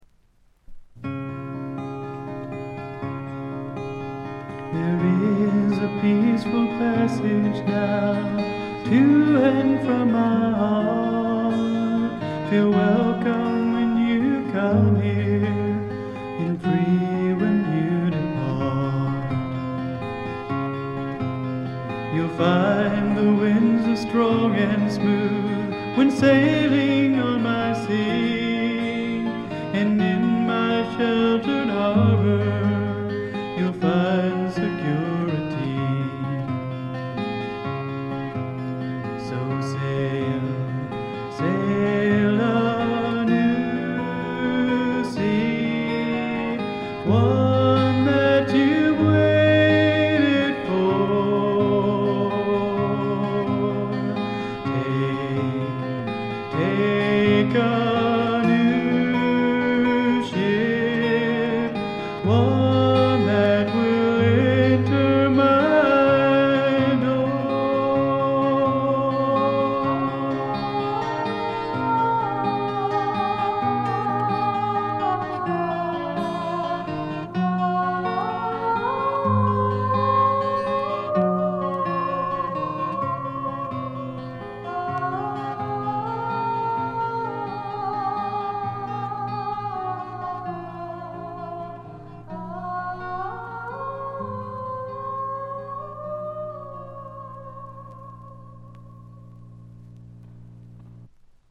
軽いバックグラウンドノイズ。
ローナーフォーク、ドリーミーフォークの逸品です。
試聴曲は現品からの取り込み音源です。